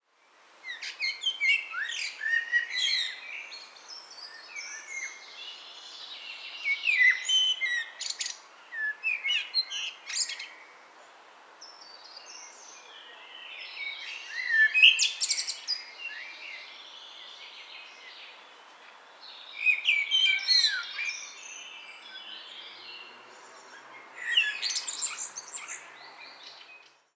Songs are more complex and melodious and are used to attract mates, protect a territory or claim a new one.
Blackbird singing.